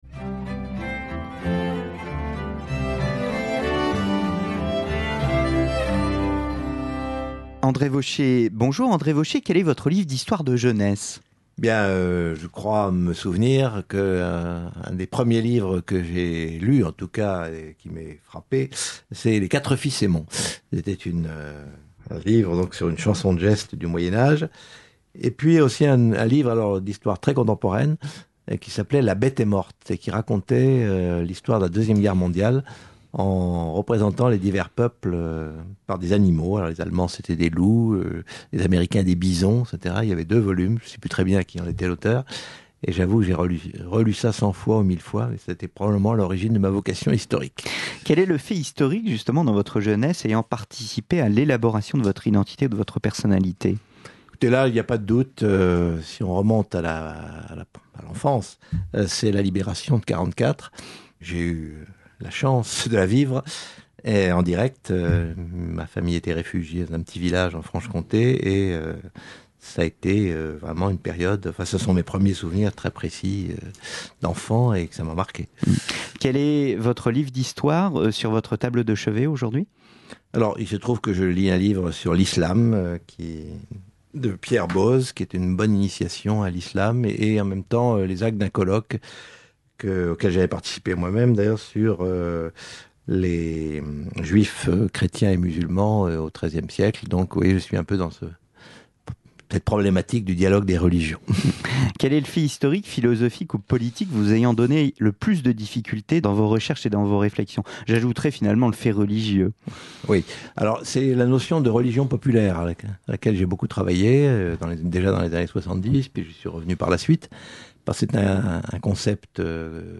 Médiéviste, membre de l'Académie des Inscriptions et Belles-Lettres, auteur notamment de "La spiritualité au Moyen-Age occidental" (éditions Point Seuil Histoire), André Vauchez répond au questionnaire des Cinq minutes pour Clio qui permettent de mieux cerner ses goûts et ses lectures en histoire comme en littérature.